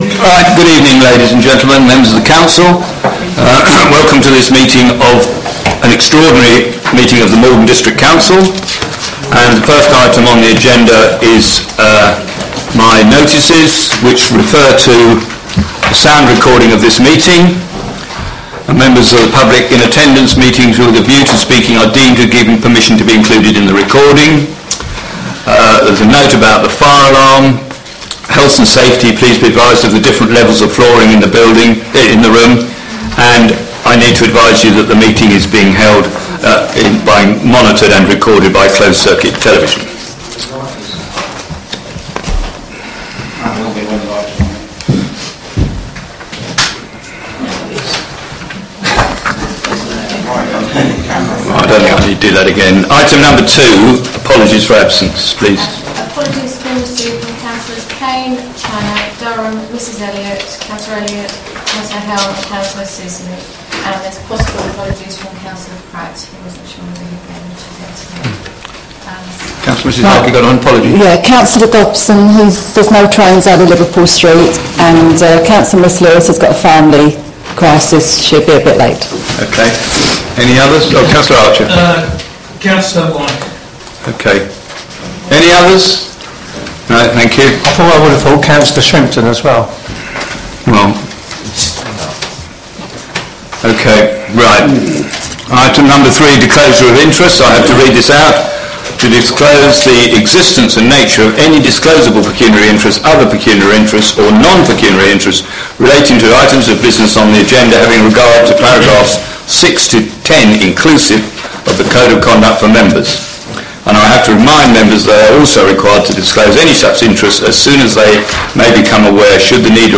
Council (Extraordinary - Planning) meeting audio recordings | Maldon District Council